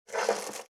555肉切りナイフ,まな板の上,包丁,ナイフ,調理音,
効果音